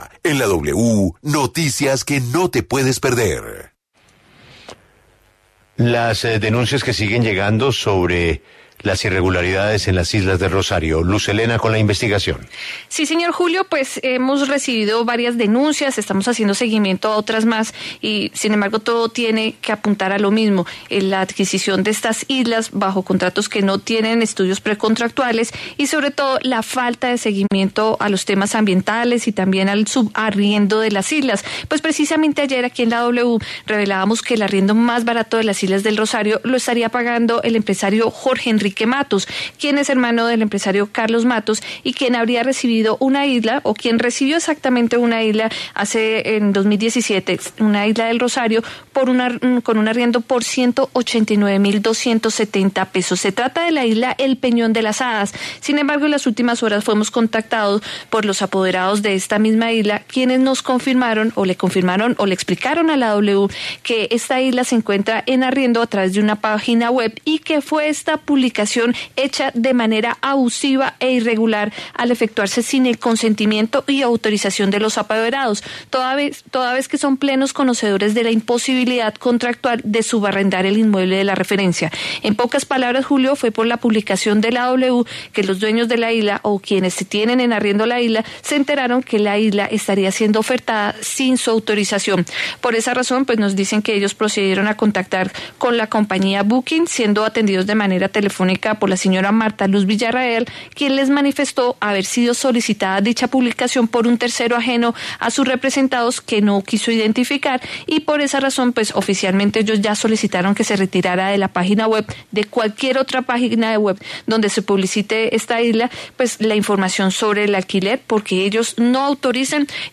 Olga Acosta, secretaria de Educación de Cartagena, habla en La W sobre las trabas para la construcción de escuelas en Islas del Rosario.